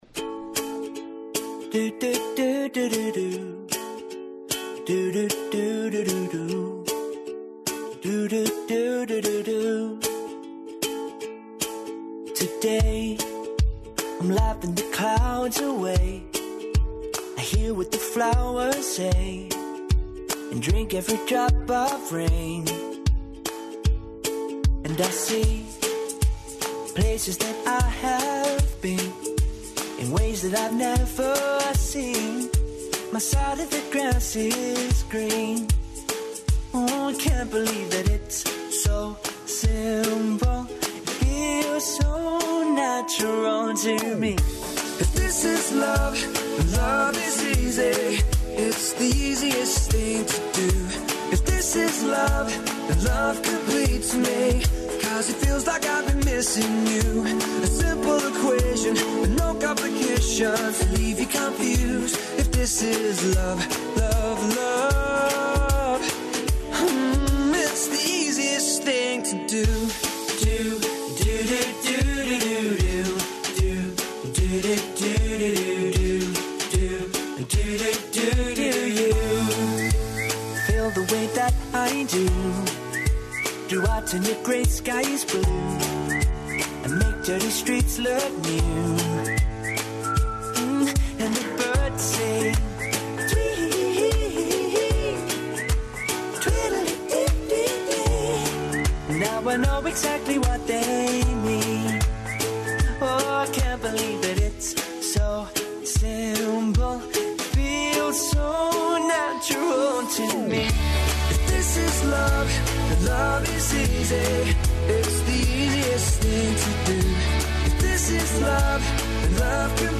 Водимо вас у 2202. годину. Послушајте како ће изгледати разговор са саговорником који је робот (тајно покушава да преузме контролу над човечанством), а како телефонско укључење из Музеја у коме је (и даље жив и здрав) Стивен Хокинг.